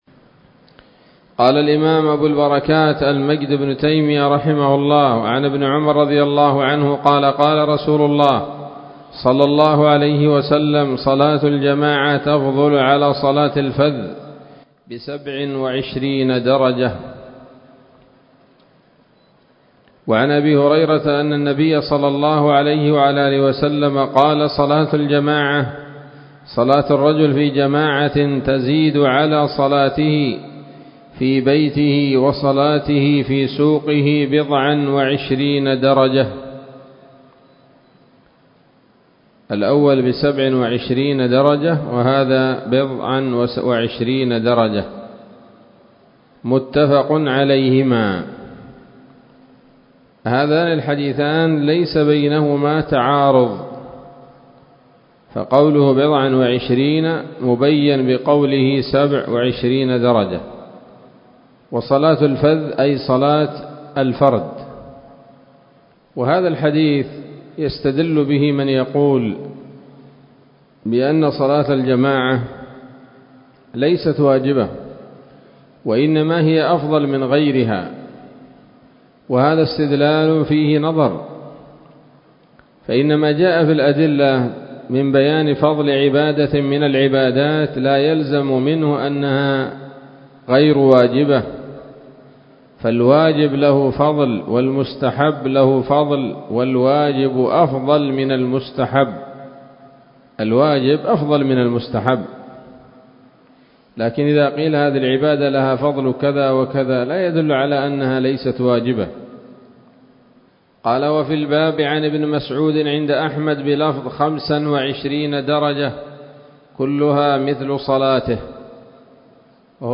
الدرس الثالث من ‌‌‌‌أبواب صلاة الجماعة من نيل الأوطار